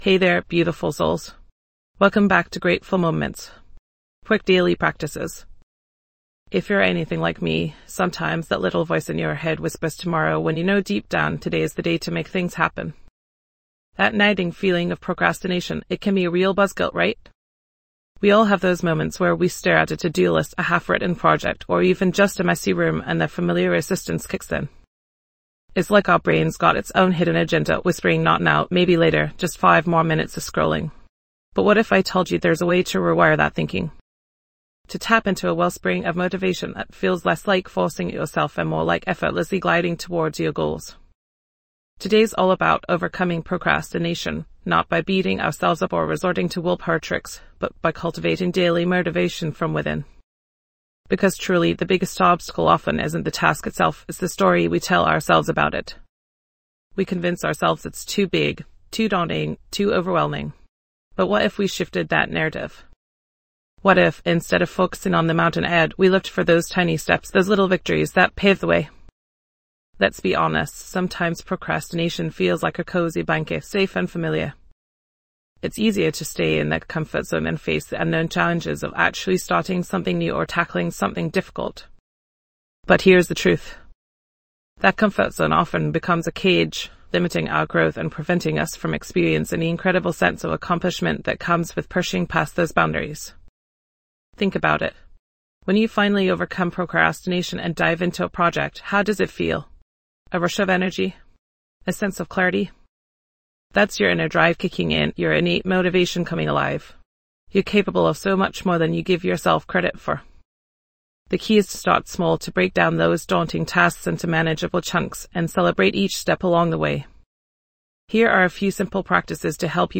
"Grateful Moments: Quick Daily Practices" offers bite-sized meditations and simple exercises designed to shift your focus to the good in your life.